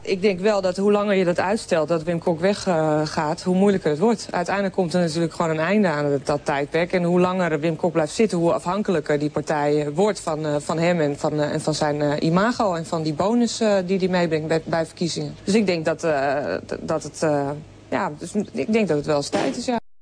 1. Poldernederlands is een vorm van gesproken Nederlands die van het ABN verschilt doordat de tweeklanken ei (ook gespeld ij), ui en ou (ook gespeld au) met een wijdere mond gearticuleerd worden en daardoor in meer of mindere mate klinken als aai, ou en aau, fonetisch gespeld [ai], [Ay] and [au]. De verlaagde diftongen zijn niet langer dan die van het ABN
Beluister nu een modelspreekster van het Poldernederlands: